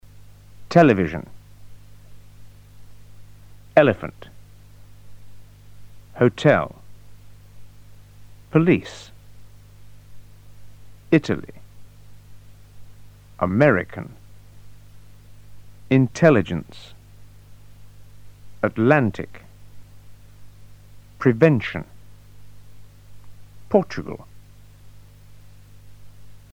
word_stress_01.mp3